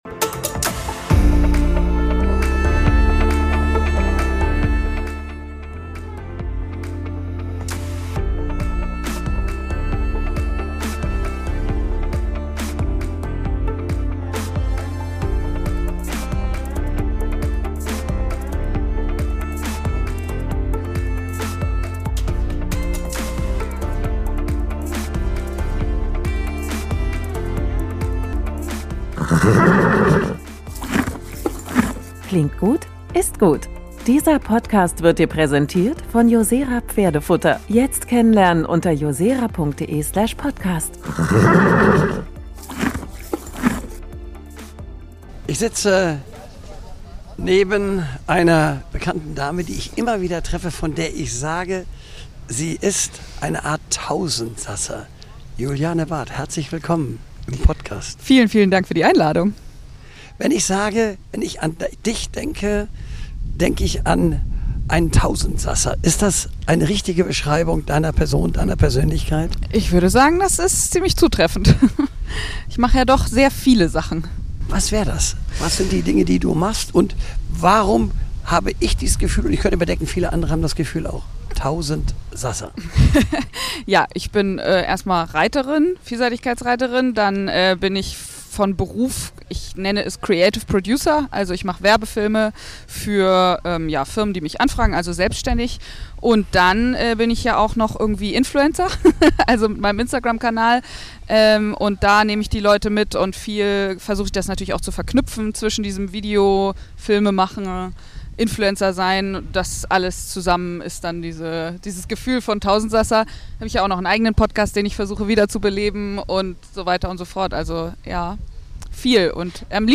Außerdem geht es um die Zukunft des Reitsports, Pferdewohl und die Verantwortung jedes Einzelnen. Ein Gespräch voller Leidenschaft, Offenheit und Inspiration – für alle, die Pferde lieben und den Sport mitgestalten möchten.